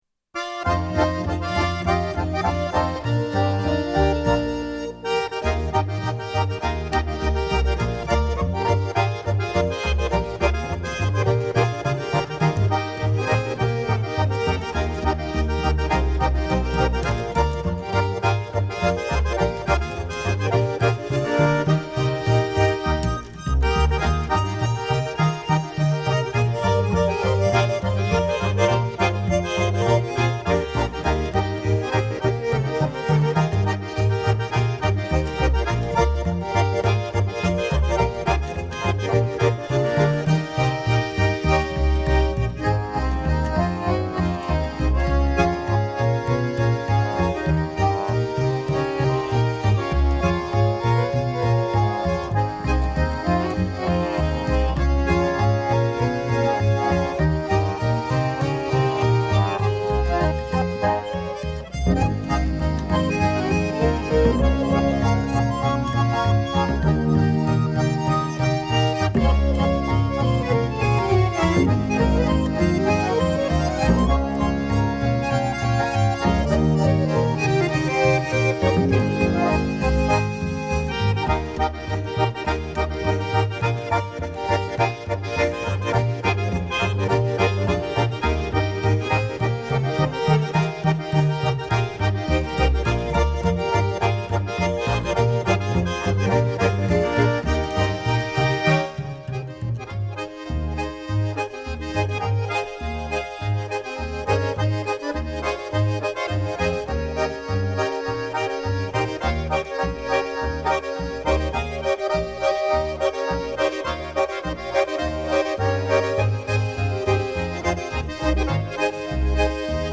die etwas andere Volksmusik